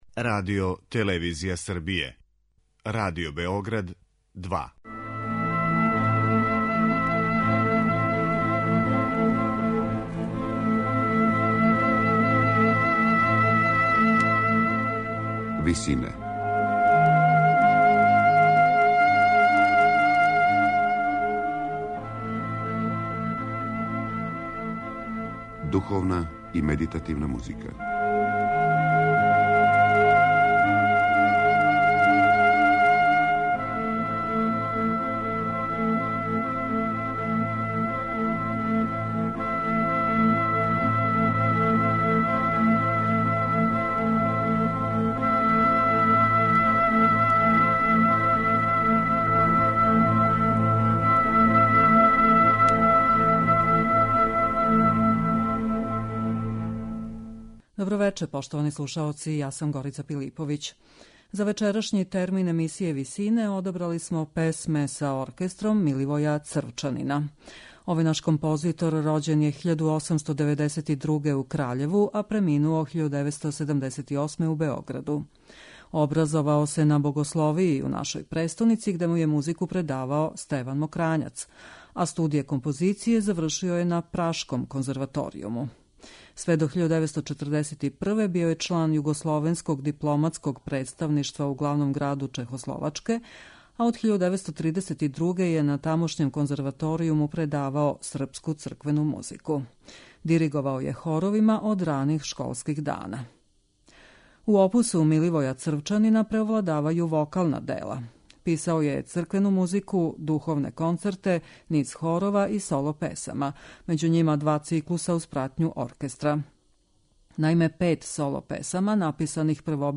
песме са оркестром